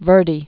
(vûrdē, vĕr-)